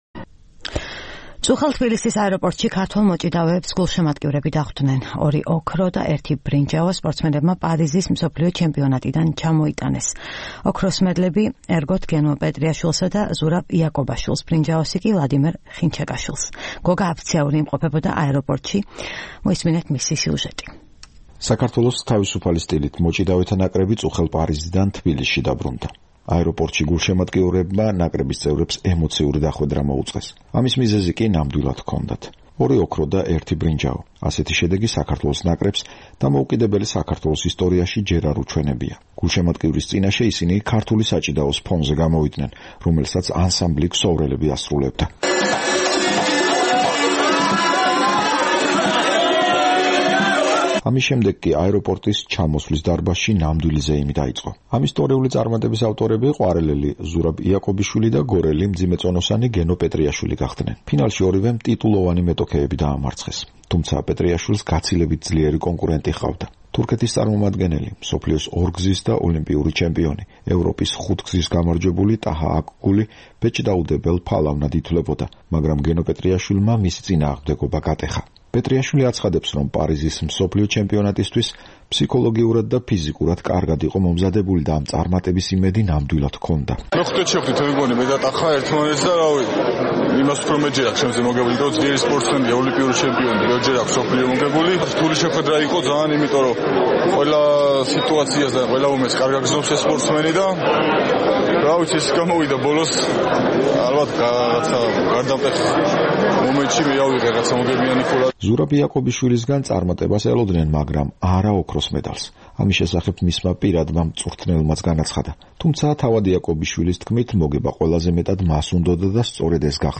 ქართველი მოჭიდავეების დახვედრა თბილისის აეროპორტში.
ამის შემდეგ კი აეროპორტში, ჩამოსვლის დარბაზში, ნამდვილი ზეიმი დაიწყო.